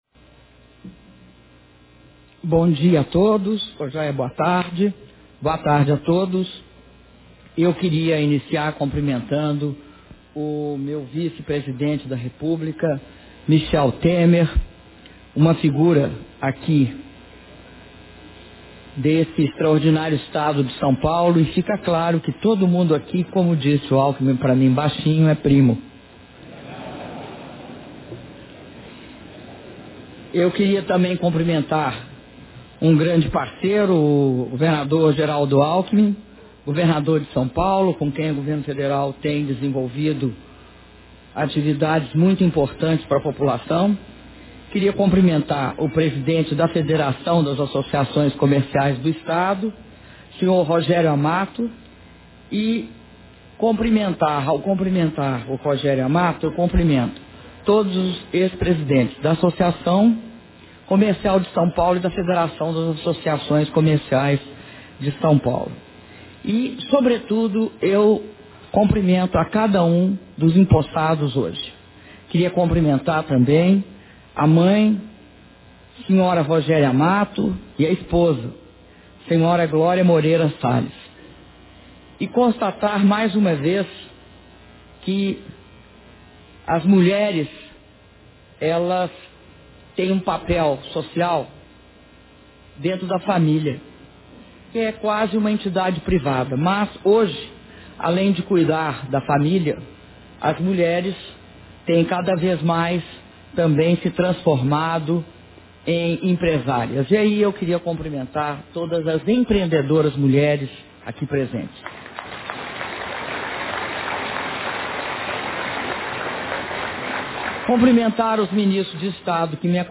Discurso da Presidenta da República, Dilma Rousseff, durante cerimônia de posse do Presidente e Diretores da Federação das Associações Comerciais do Estado de São Paulo e da Associação Comercial de São Paulo
São Paulo-SP, 06 de maio de 2013